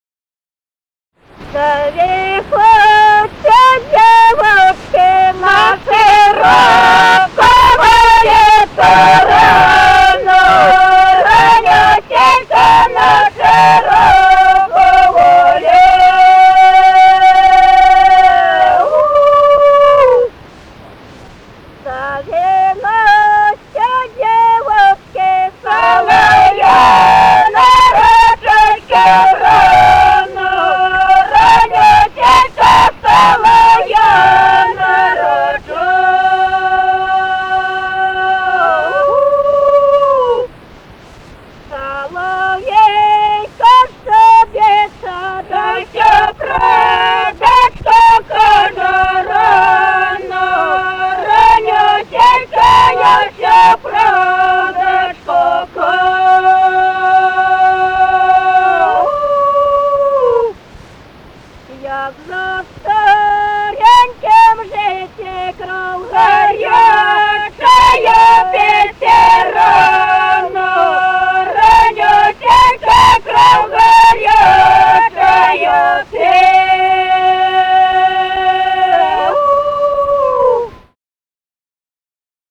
(запев). Записали участники экспедиции